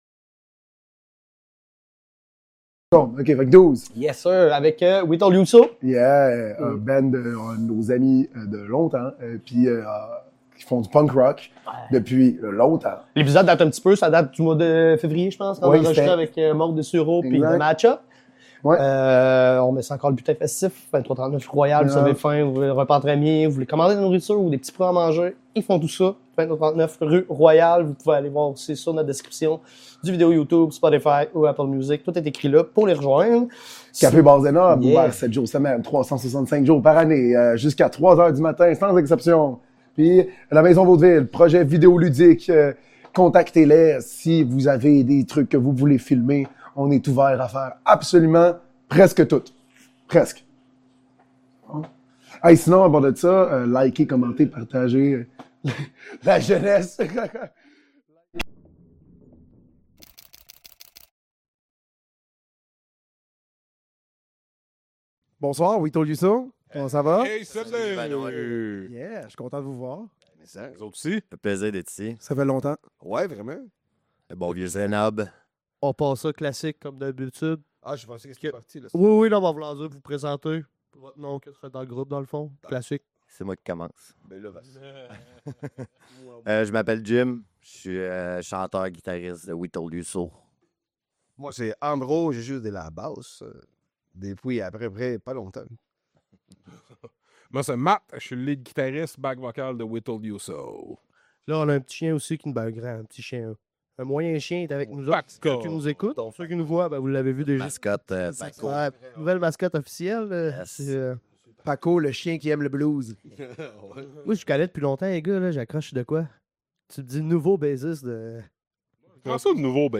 Cette semaine, nous recevons le groupe punk rock trifluvien We Told You So! Enregistré lors du spectacle du 15 mars dernier, nous discutons de l’histoire du groupe, de leurs projets à venir et partageons quelques anecdotes sur l'Amérique latine avec